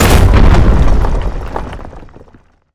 rock_earthquake_impact_02.wav